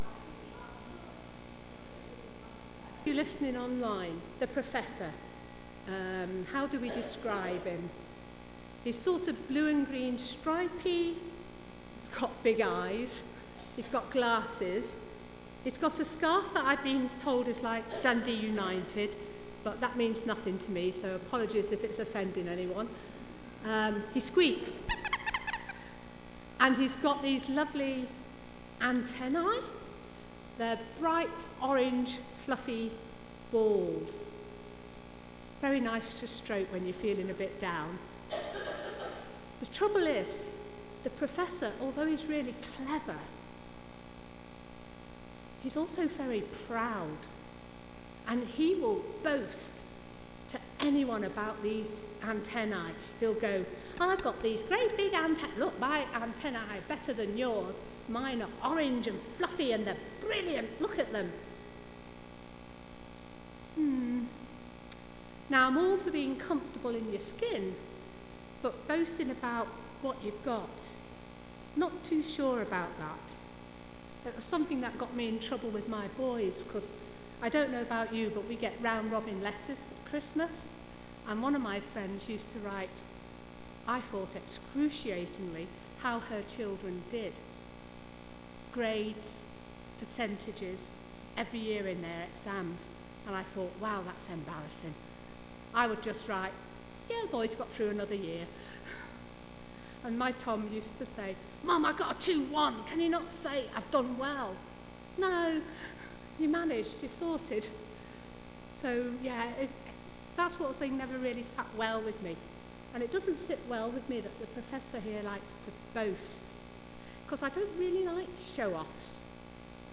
Galatians Passage: Galatians 6 Service Type: Sunday Morning « Set free